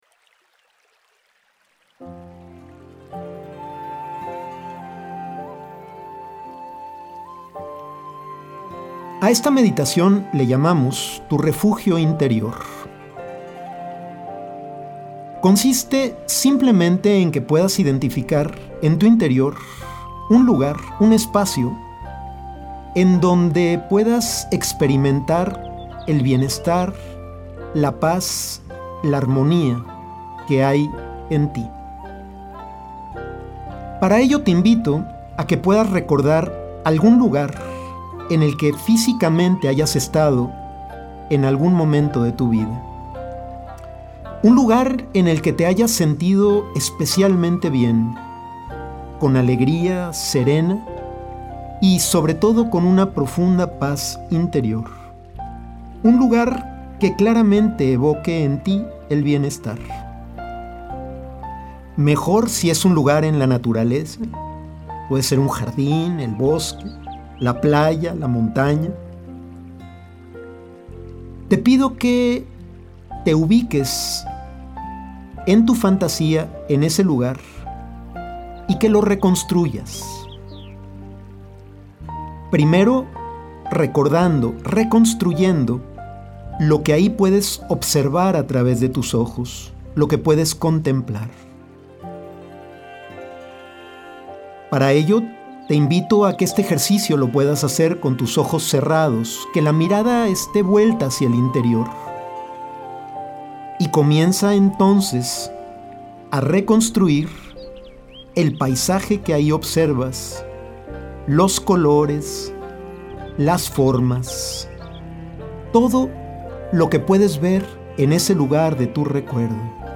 Meditación: Refugio Interior